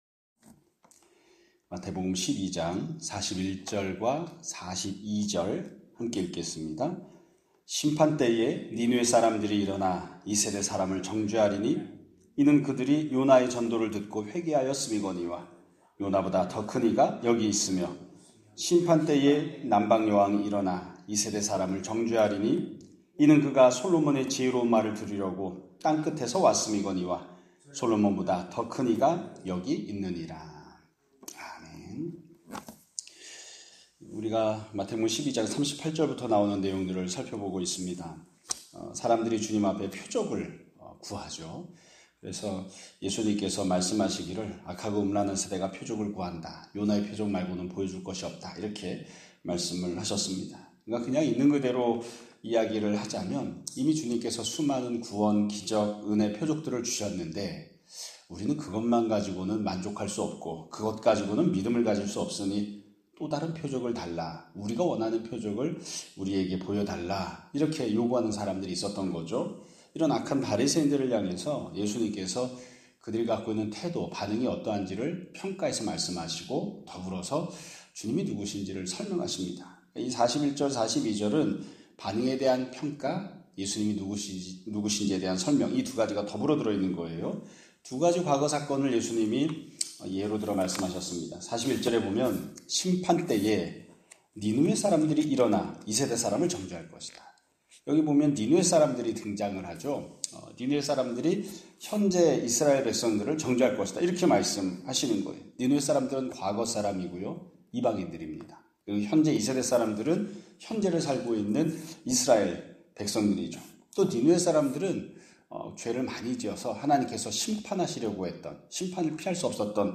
2025년 9월 18일 (목요일) <아침예배> 설교입니다.